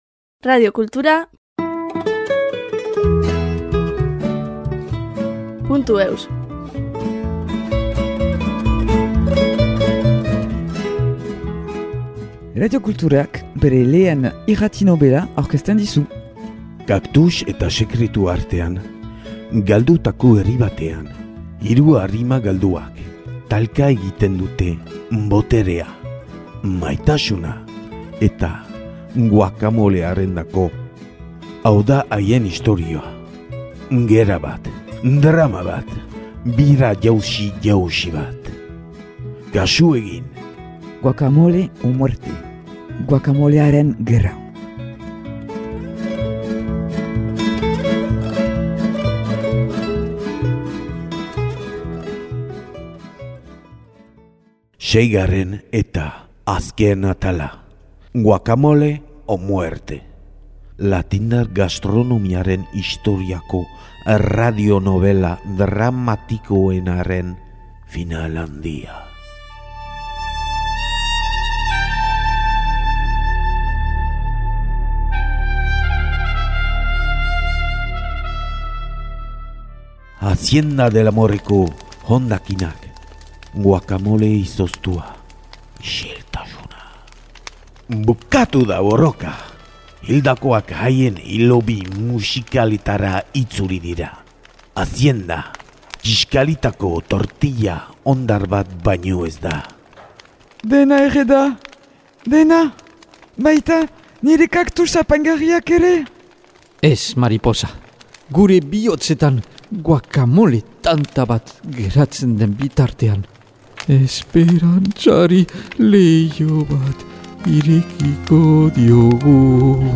Radiokulturak bere lehen irrati-nobela aurkezten dizu : Kaktus eta sekretuen artean galdutako herri batean, hiru arima galduek talka egiten dute boterea, maitasuna … eta guacamolearendako !